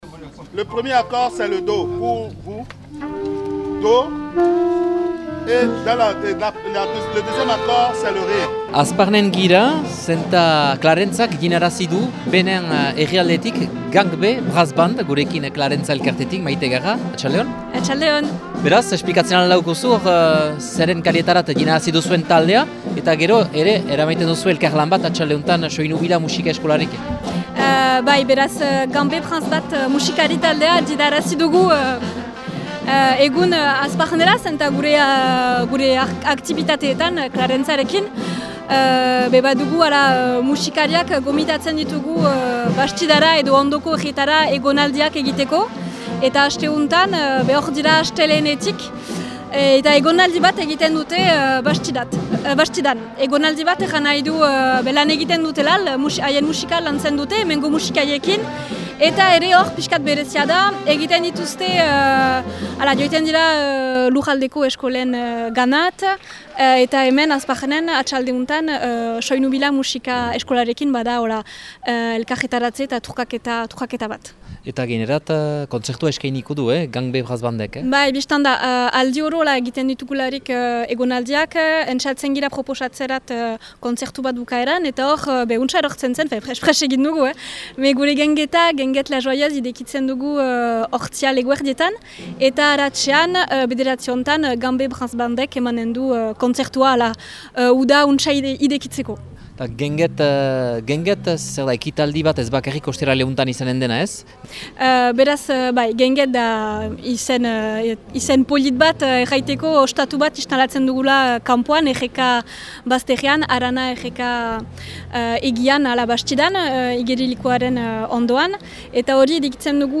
Asteazken atsaldean, Gangbé Brass Band eta Hazparneko Soinu Bila musika eskolako ikasleek elgarrekin errepikatu dute taldearen musika zati bat, bi orenen buruan elgarrekin emaiteko.